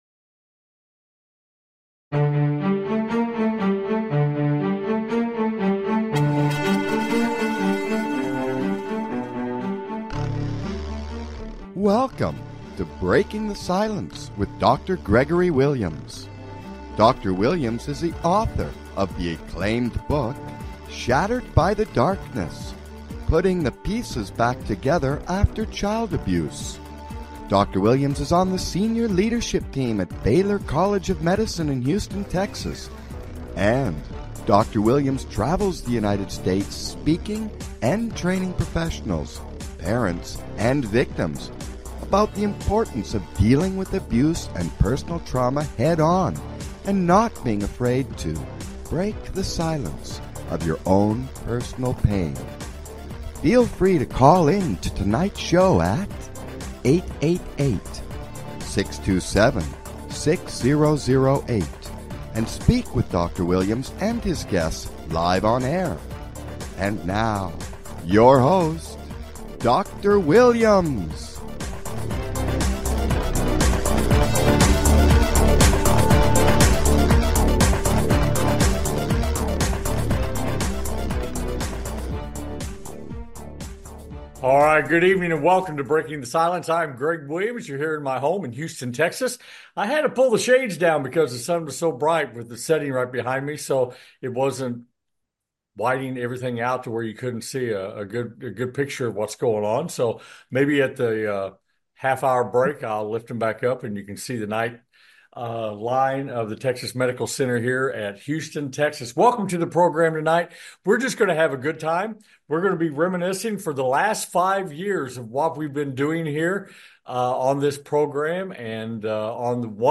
Breaking the Silence 5th Anniversary Special joined by past guests to reminisce and discuss the past 5 years the show
On this episode of Breaking the Silence we will be celebrating our 5 years of LIVE radio on the BBS Radio TV Network.